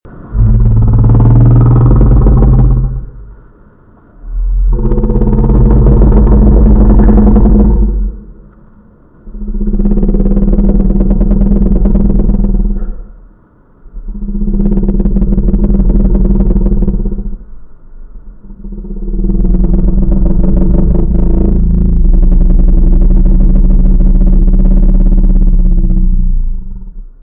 Sound Effects
Creaking Growl